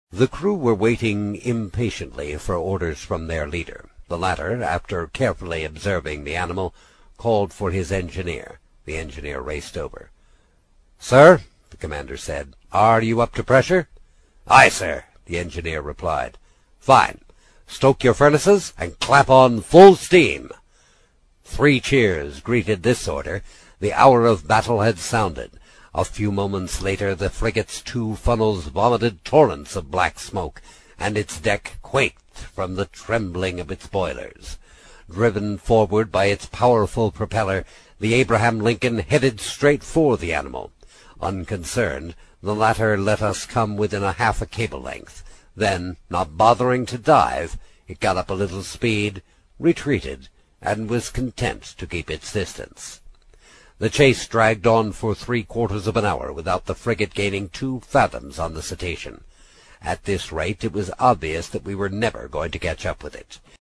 英语听书《海底两万里》第69期 第6章 开足马力(11) 听力文件下载—在线英语听力室